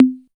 78 808 TOM.wav